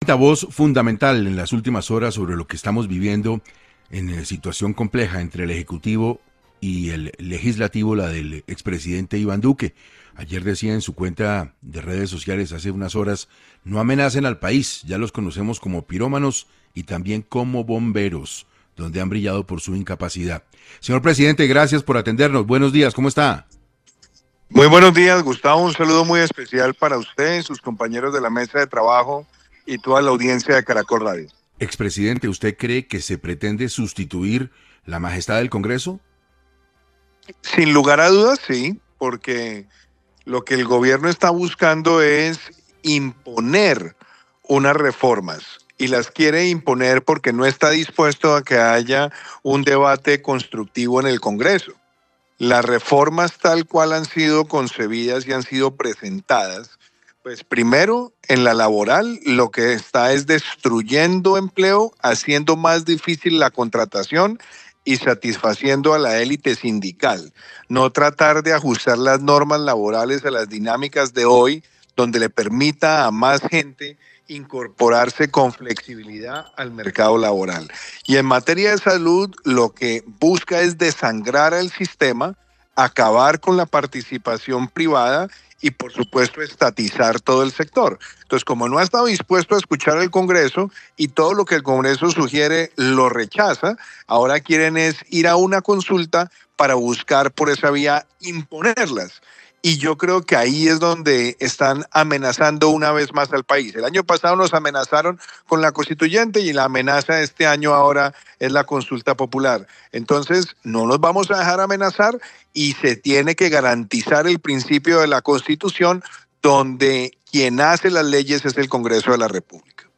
En entrevista para 6 AM, el expresidente, Iván Duque, habló sobre cómo el gobierno Petro amenazan al país con la propuesta de consulta popular.